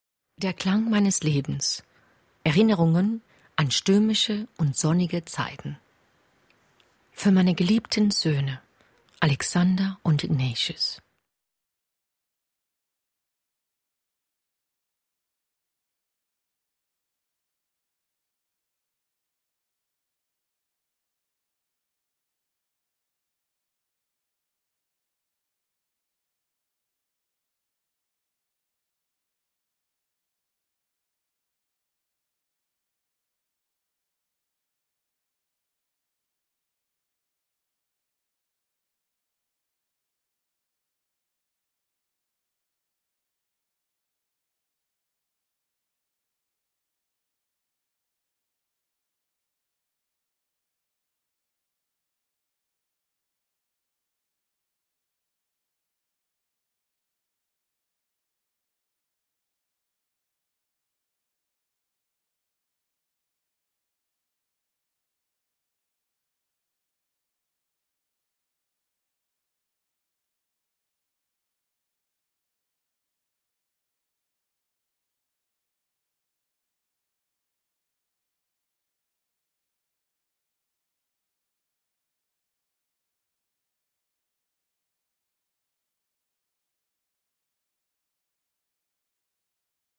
Der Klang meines Lebens - Hörbuch
Hörprobe: Prolog